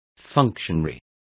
Complete with pronunciation of the translation of functionary.